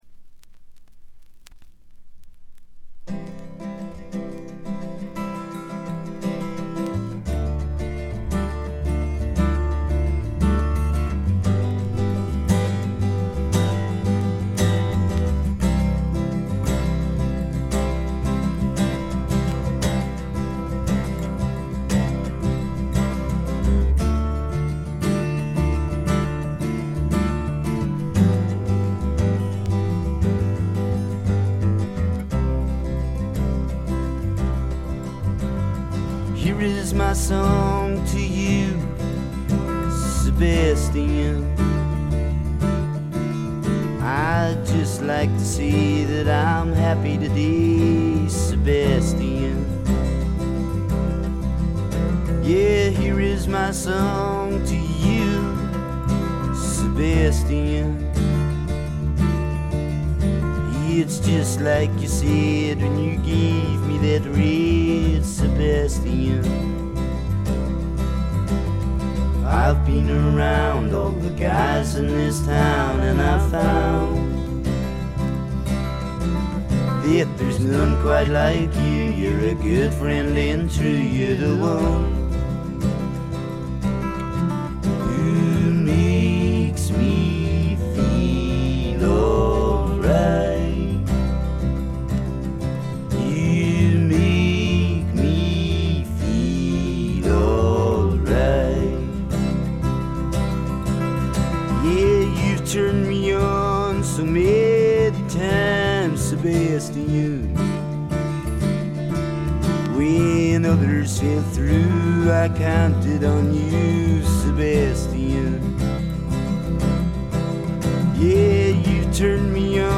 ところどころで軽微なチリプチ。
英国シンガー・ソングライター／フォークロックの至宝。
試聴曲は現品からの取り込み音源です。